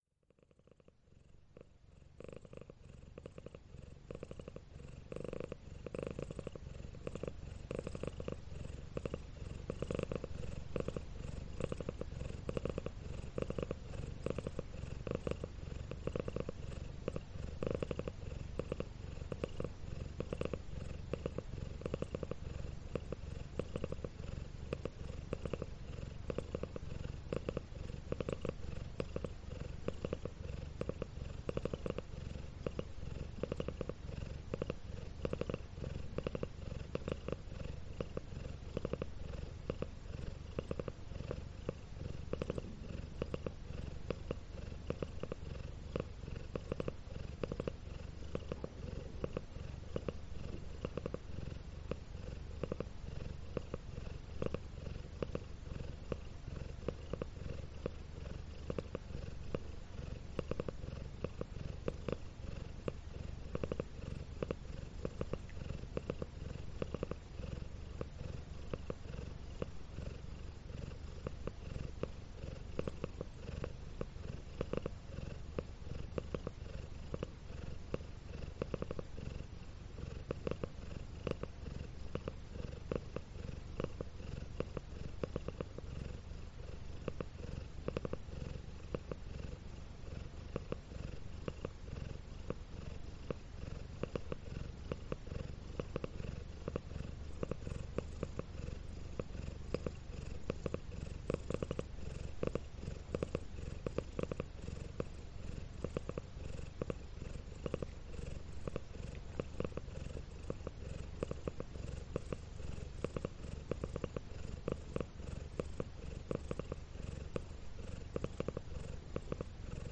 Die Katze, sie schnurrt (Ambient Sound)
Katzenschnurren
Katzenschnurren.mp3